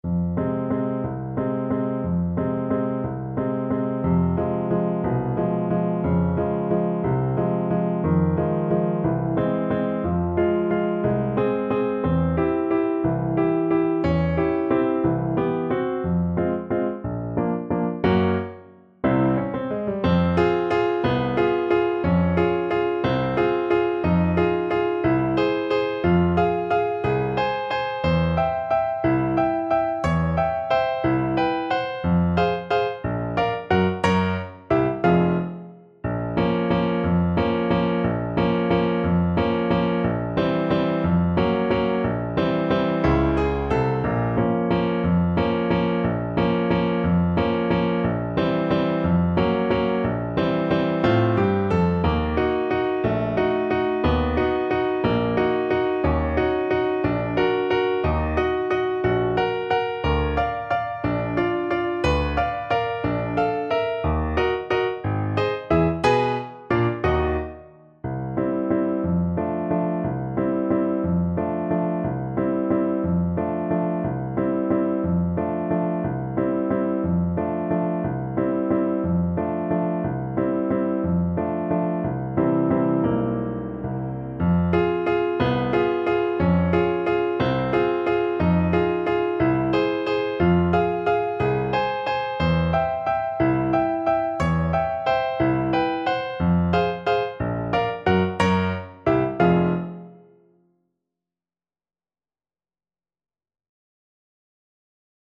3/4 (View more 3/4 Music)
Allegro espressivo .=60 (View more music marked Allegro)
Classical (View more Classical Saxophone Music)